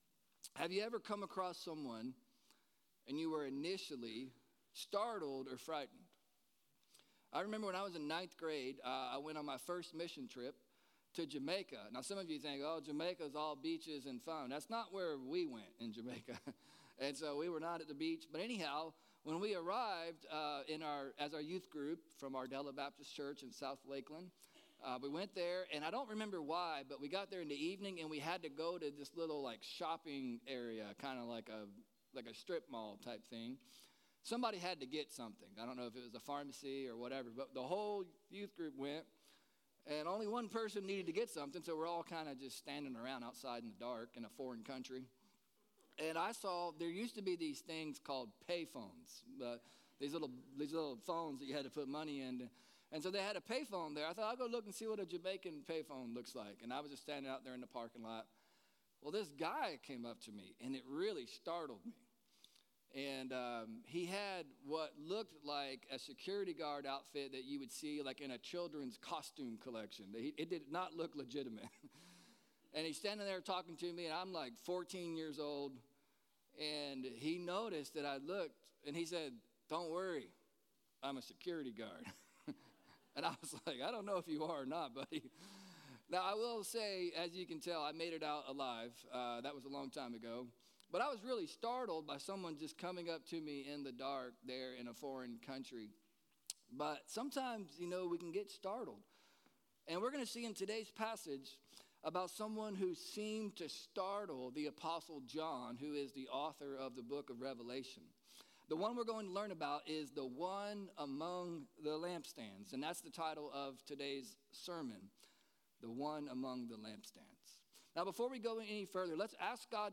Sermon-Rev-19-20.mp3